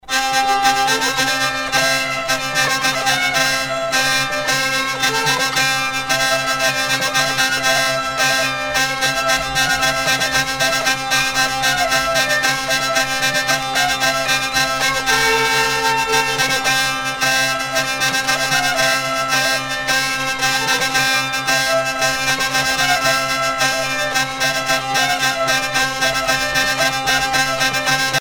danse : passepied
Sonneurs de vielle traditionnels en Bretagne
Pièce musicale éditée